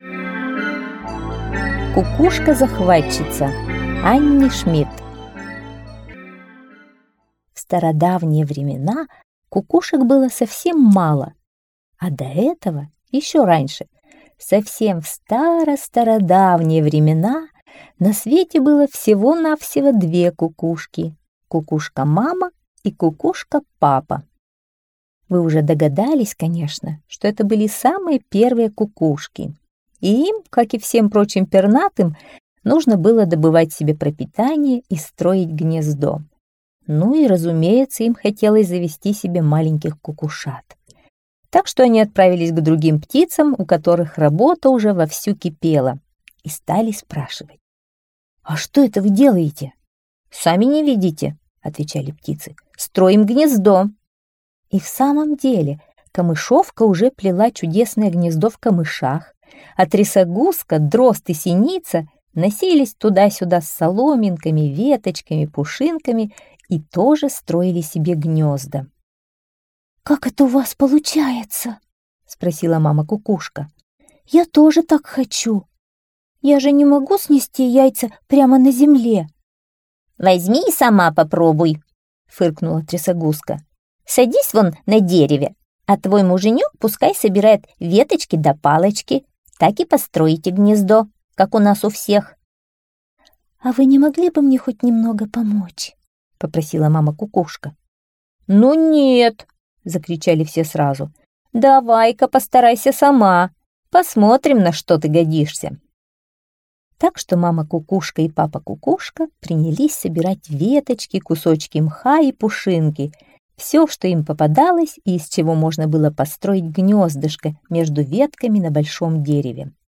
Кукушка-захватчица - аудиосказка Шмидт - слушать онлайн